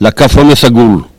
ambiance et archives
Catégorie Locution